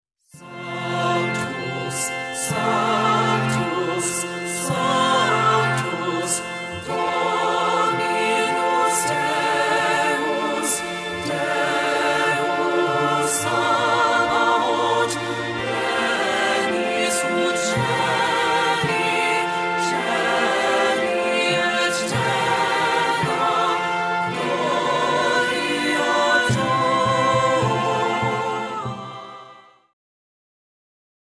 • Full Mix Track